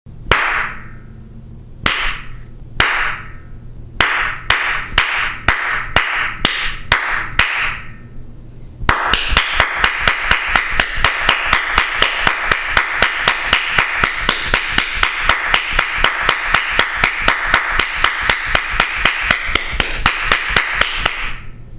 دانلود صدای دست زدن 4 از ساعد نیوز با لینک مستقیم و کیفیت بالا
جلوه های صوتی
برچسب: دانلود آهنگ های افکت صوتی انسان و موجودات زنده دانلود آلبوم صدای دست زدن و تشویق از افکت صوتی انسان و موجودات زنده